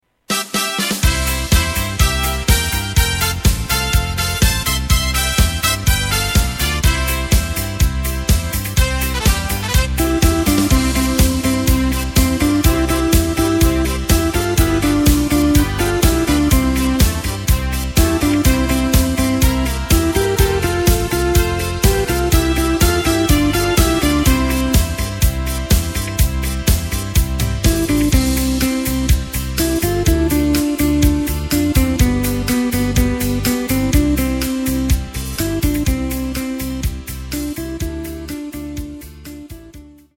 Takt:          2/4
Tempo:         124.00
Tonart:            C
Schlager aus dem Jahr 1969!